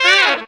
HARM RIFF 13.wav